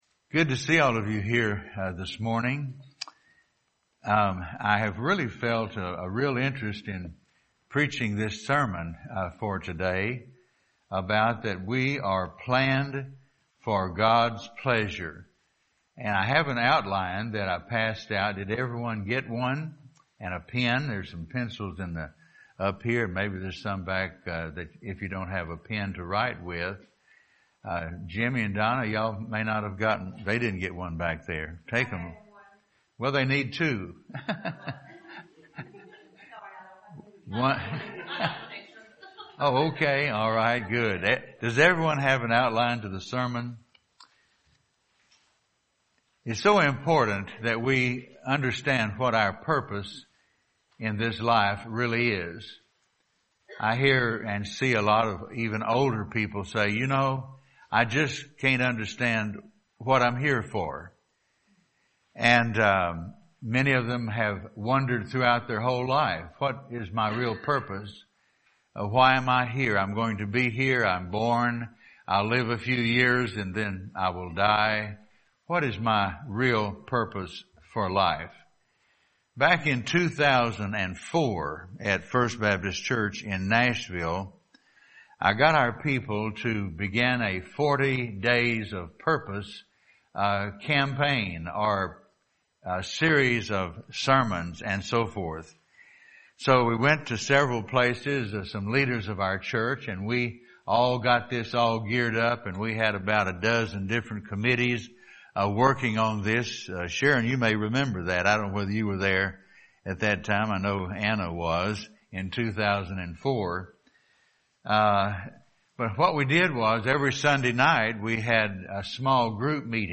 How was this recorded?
Passage: Revelation 4:11; Matt. 22:35-38 Service Type: Sunday Morning